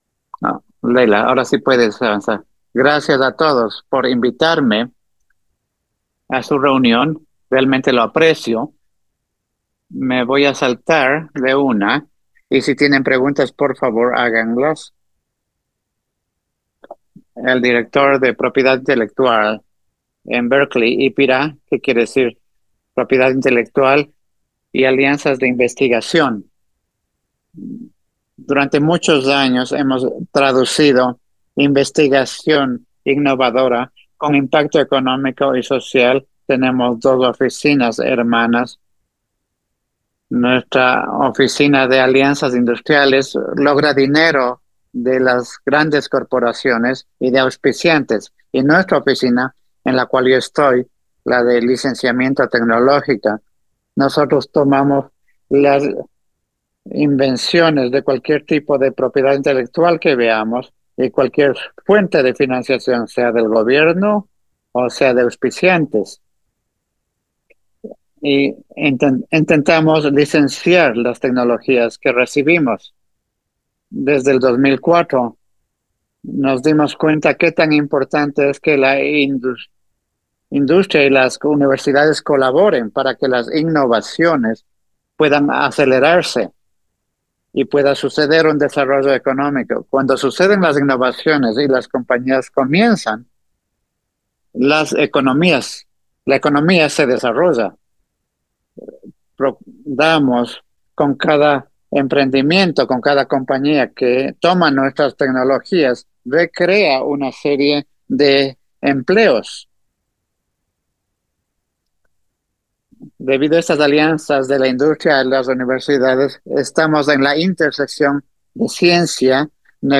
Audio-Interpretacion-Espanol.mp3